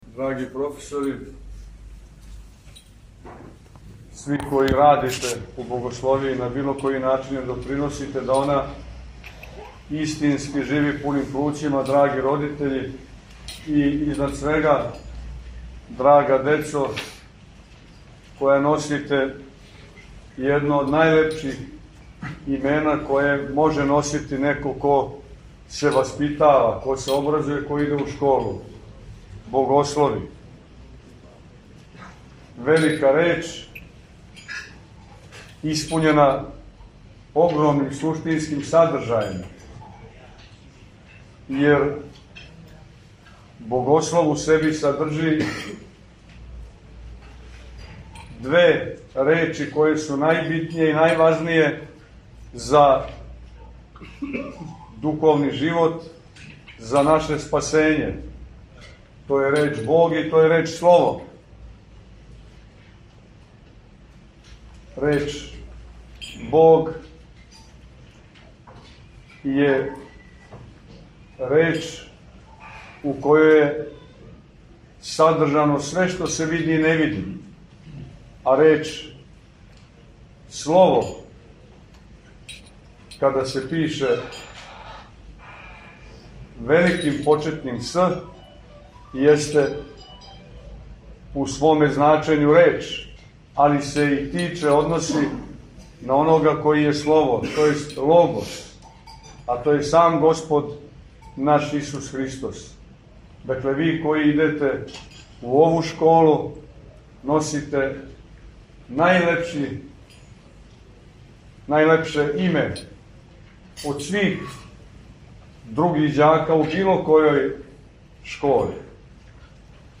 Његова Светост Патријарх српски г. Порфирије је данас, 1. септембра 2025. године, служио свету архијерејску Литургију у капели Богословије Светога Саве у Београду. После читања светог Јеванђеља, Светејши Патријарх се обратио ученицима, професорима и родитељима беседом о томе да је звање богослова једно од најузвишенијих јер у себи садржи не само стицање знања, већ и позив на живот у Христу и сведочење Јеванђеља. Патријарх је посебно нагласио да бити богослов, а онда и свештеник, није само професија или занимање, већ призив, позив и служба Господу.